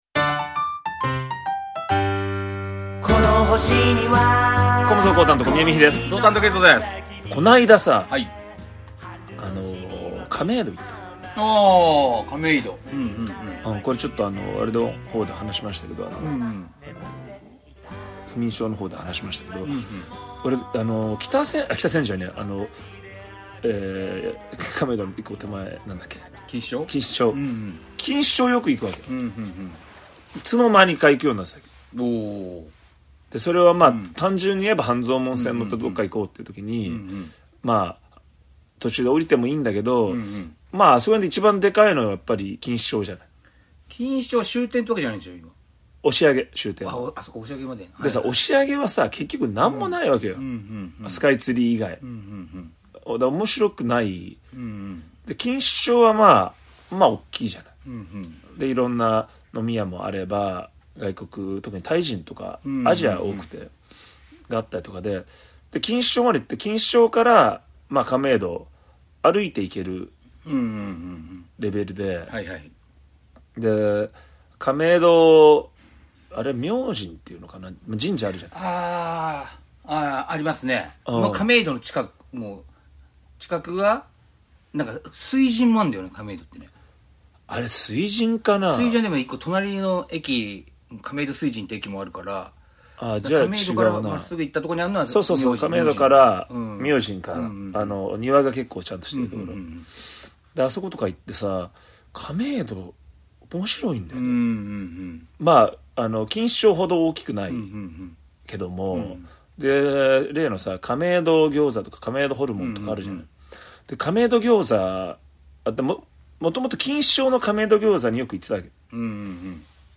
による男3人のダラダラトーク。